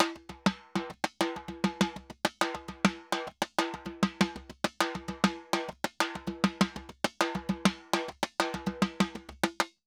Timba_Candombe 100_1.wav